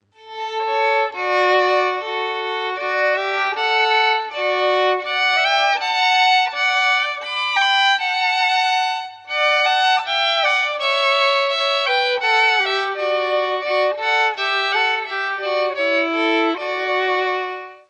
Musik aus dem Mostviertel